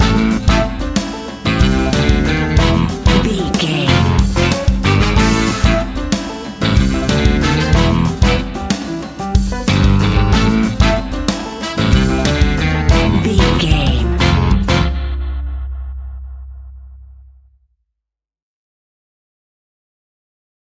Aeolian/Minor
latin
uptempo
drums
bass guitar
percussion
brass
saxophone
trumpet